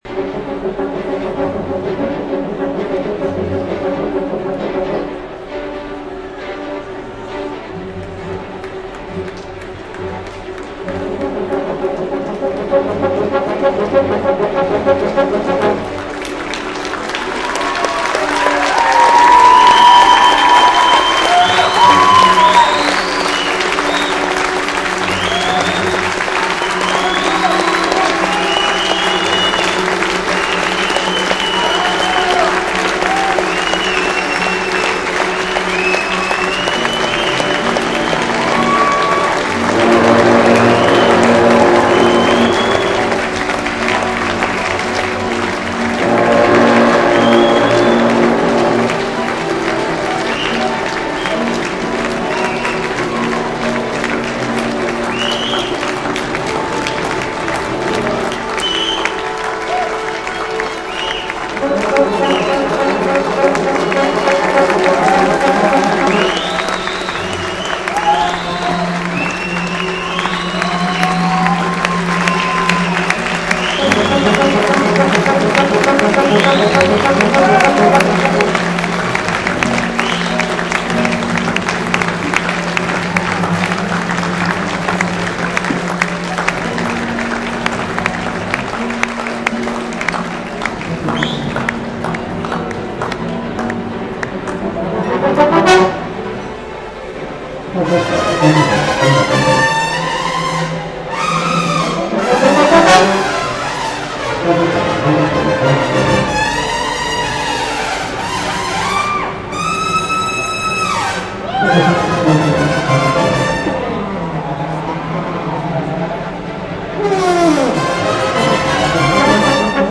The End of the Line - RNCM Premiere at Manchester Piccadilly - Encore